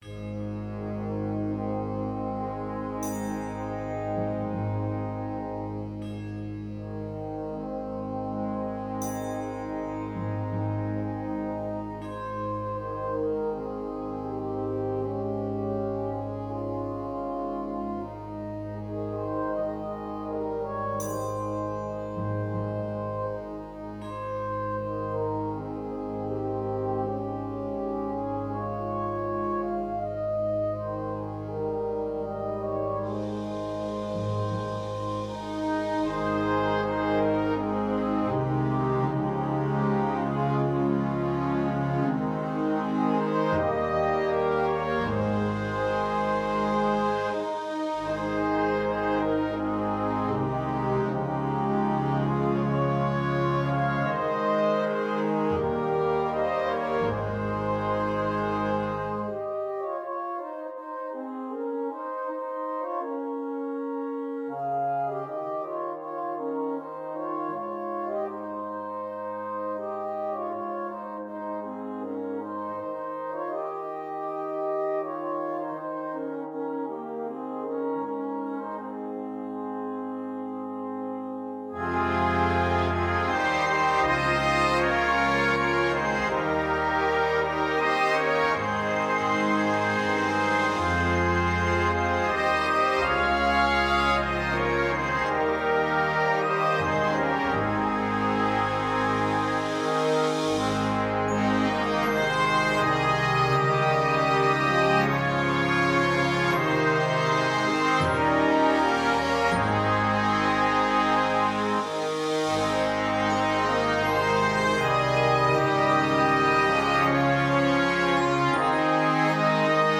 Gattung: Choral für Blasorchester
Besetzung: Blasorchester